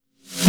Track 08 - Reverse Snare OS.wav